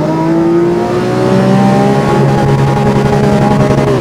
Index of /server/sound/vehicles/vcars/lamboaventadorsv